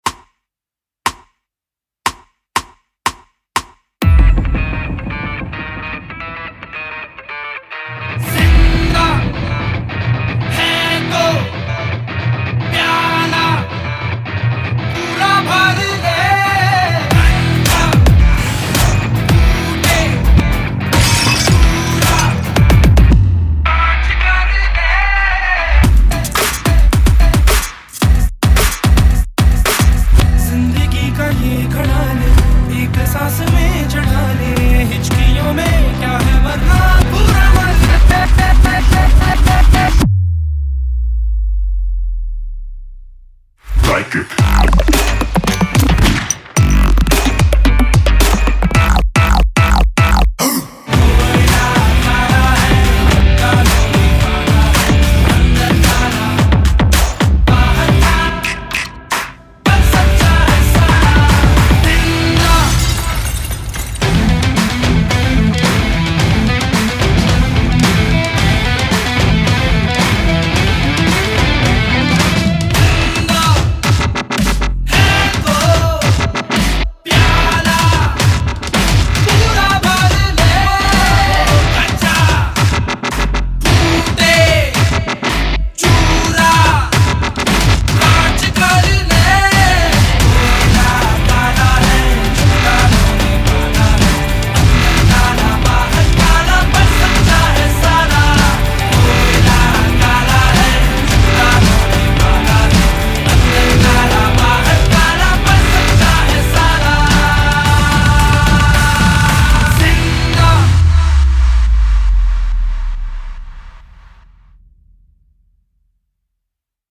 Hip Hop-Break Beat Mp3 Song